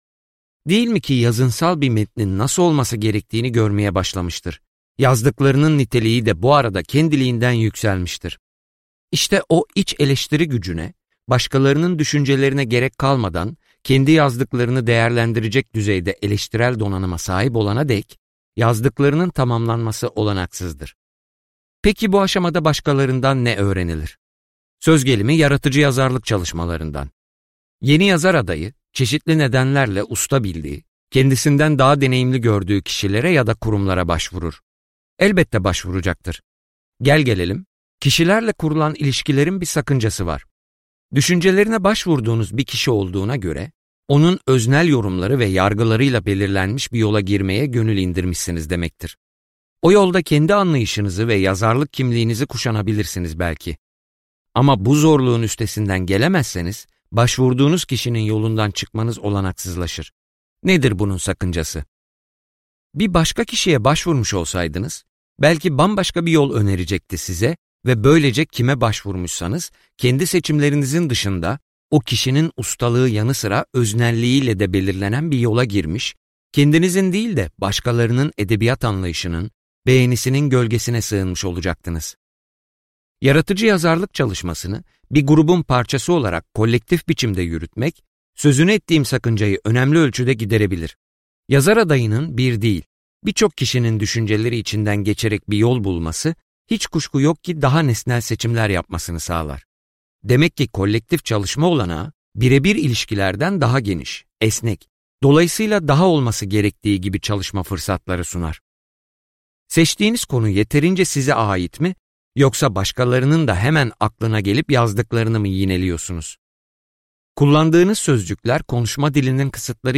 - Seslenen Kitap